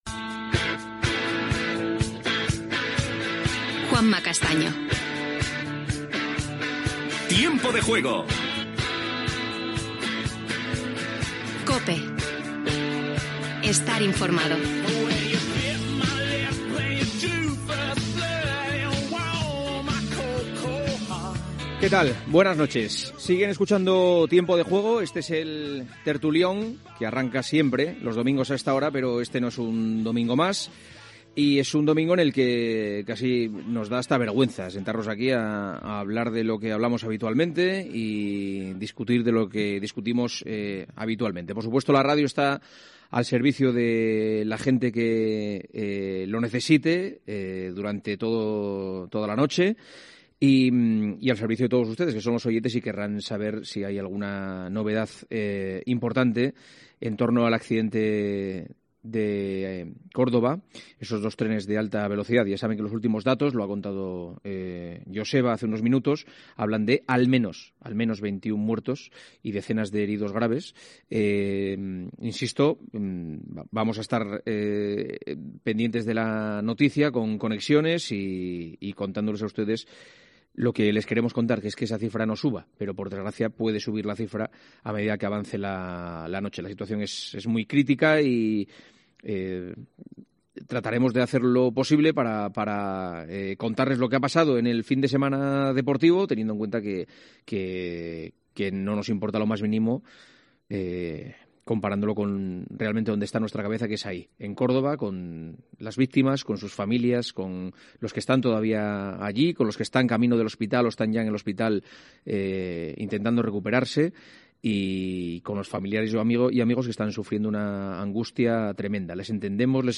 Indicatiu del programa i de la cadena, secció "El tertulión". Comentaris sobre l'accident de dos trens d'alta velocitat a Ademuz (Còrdova, Andalusia)
Esportiu
FM